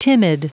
Prononciation du mot timid en anglais (fichier audio)
Prononciation du mot : timid